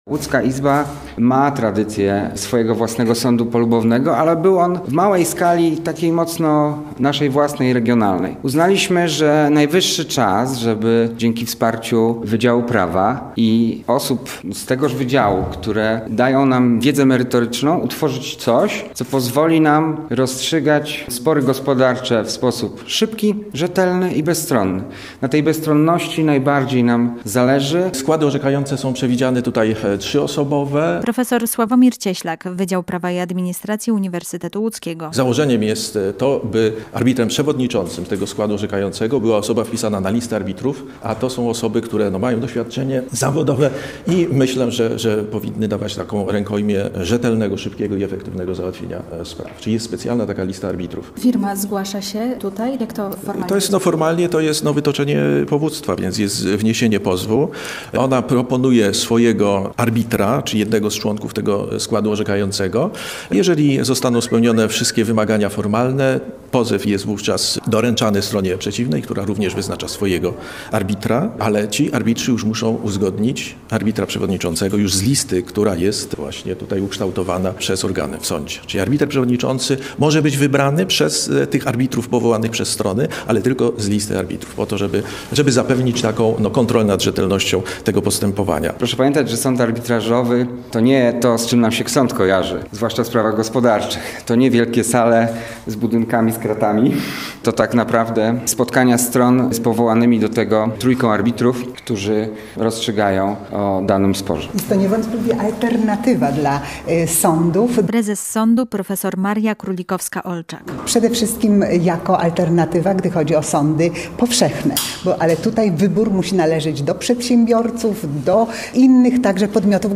Posłuchaj relacji i dowiedz się więcej: Nazwa Plik Autor Sąd arbitrażowy audio (m4a) audio (oga) ZDJĘCIA, NAGRANIA WIDEO, WIĘCEJ INFORMACJI Z ŁODZI I REGIONU ZNAJDZIESZ W DZIALE “WIADOMOŚCI”.